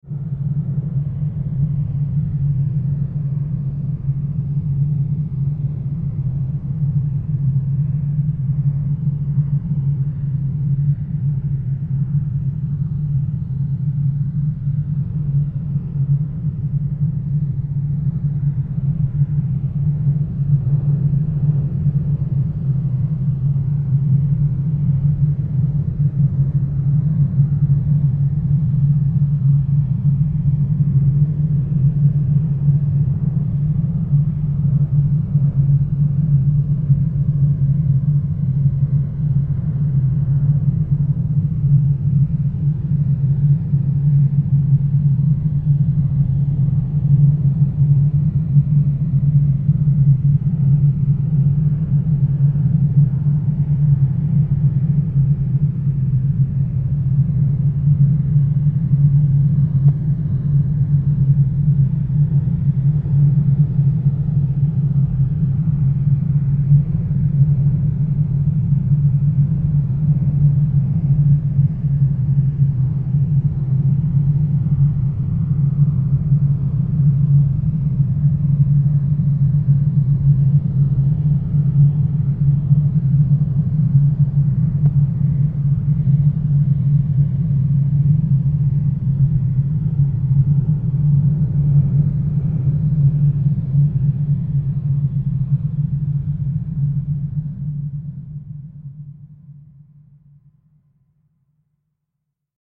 Premium Quality Futuristic Extended Sound Effect In WAV Format.
Channels: 2 (Stereo)
A Professional Sounding Futuristic STEREO Sound Effect.
Tags: alien beam beams future futuristic laser sci-fi science fiction scifi sfx sound sound effect sound fx soundfx stolting space spacecraft spaceships
PLRSound-com-Sci-Fi-SFX-846.mp3